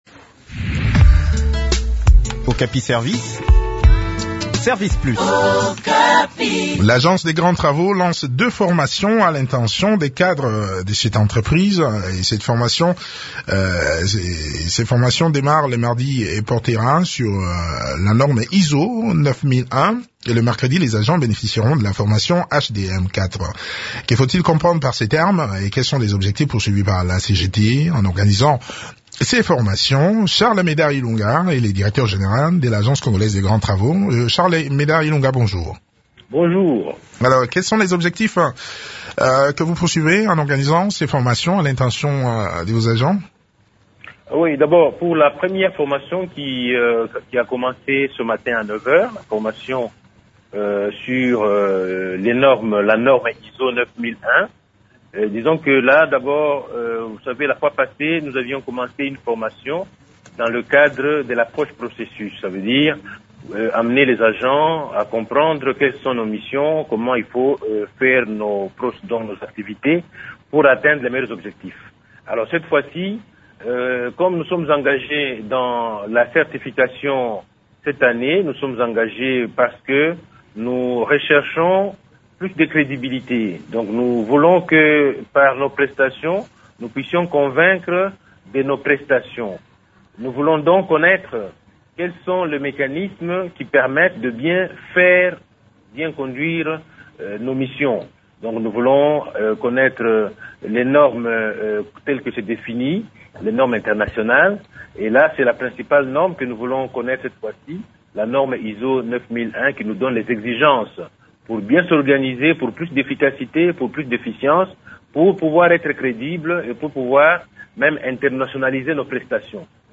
Le point sur le déroulement de cette session de formation dans cet entretien